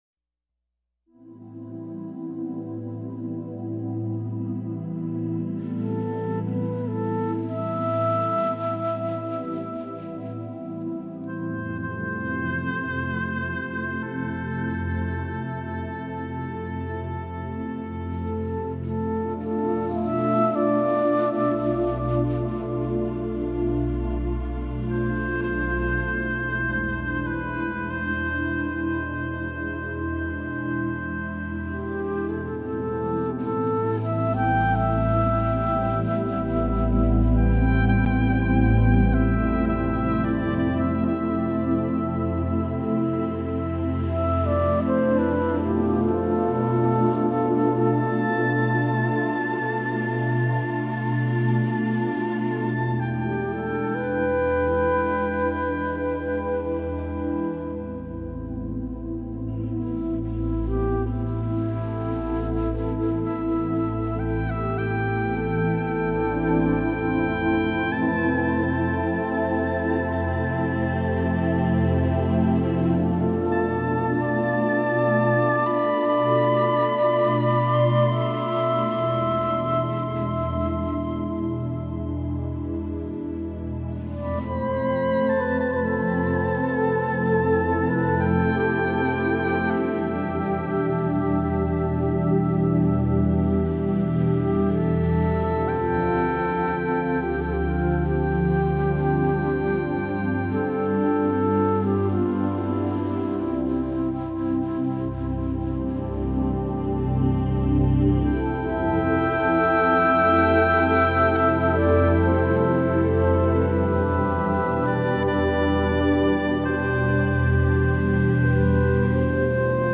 音乐类型: New Age
他们将长笛和钢琴交融的情绪流在一起，所营造的平静舒缓旋律，一直是治疗类作品之精品。